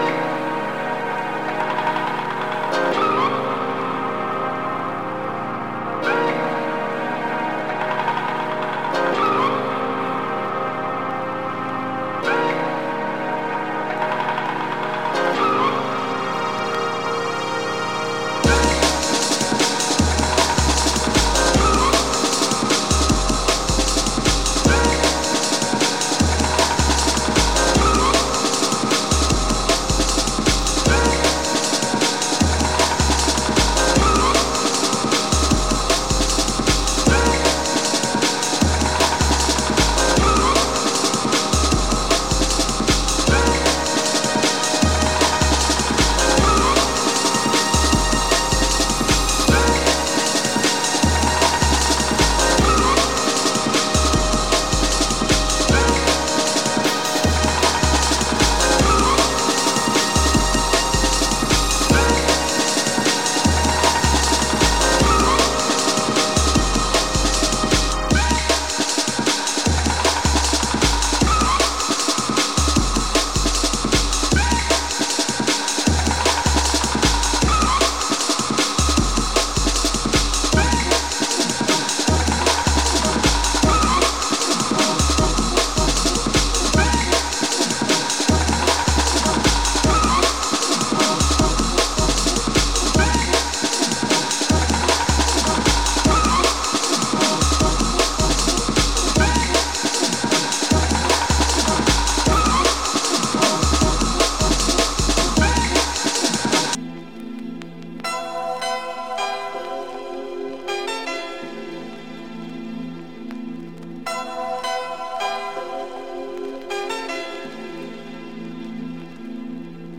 Light surface marks cause some very light crackles.